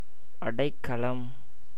pronunciation transl.